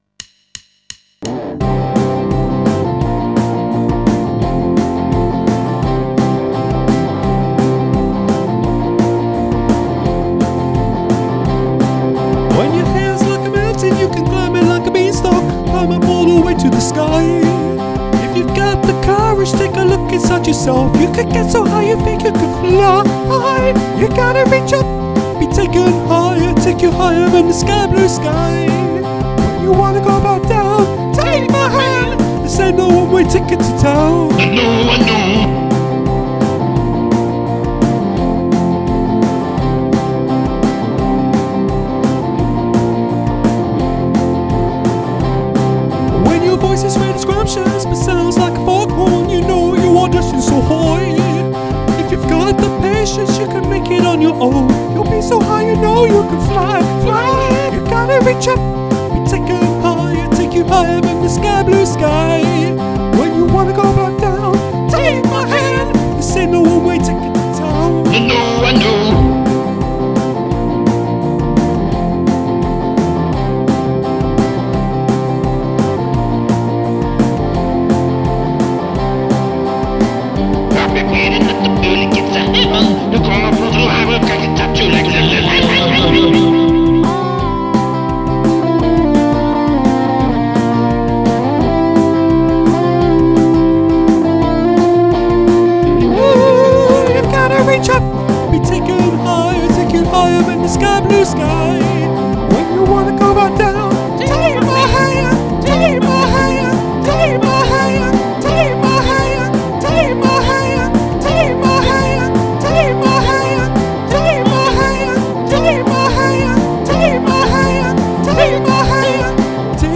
Oh this might just be THE happiest tune ever composed.
super light and fluffy, major key pop-soul banger